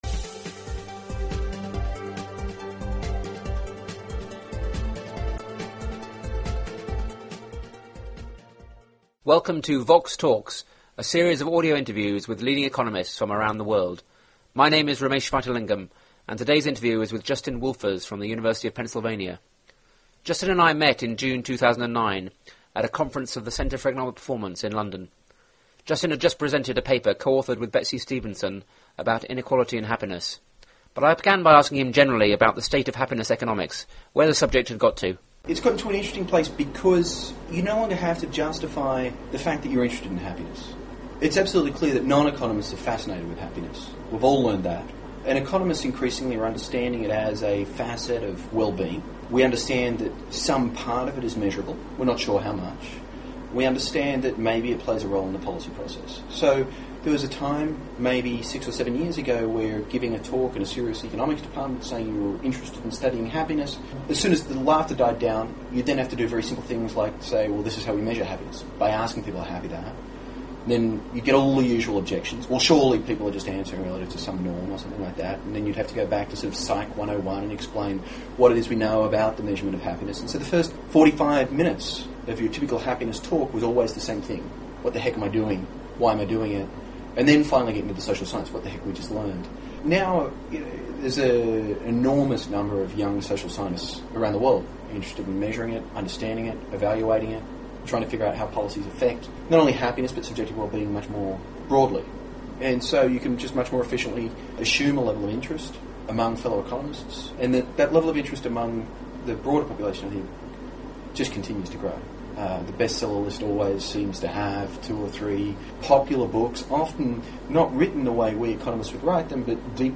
The interview was recorded at the Centre for Economic Performance in London in June 2009.